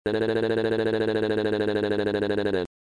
The sound is unexplainable, and weird.